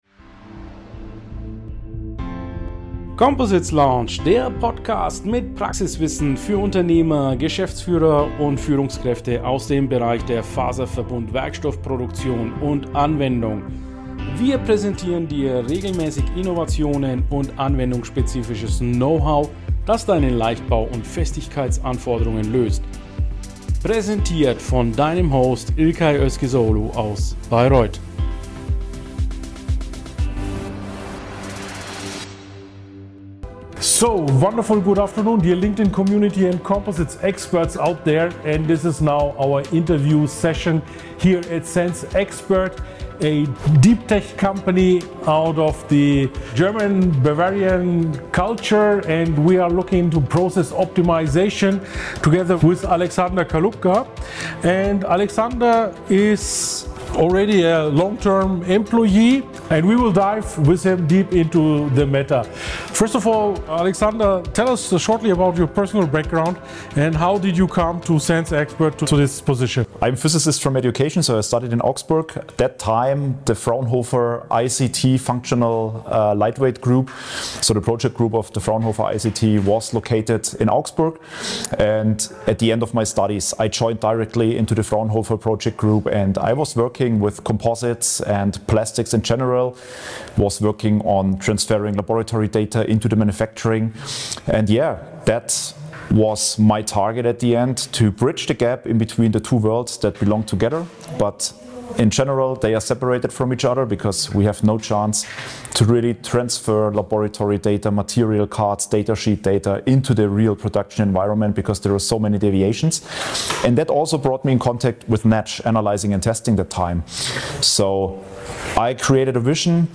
We thank the JEC Group for the stage recording.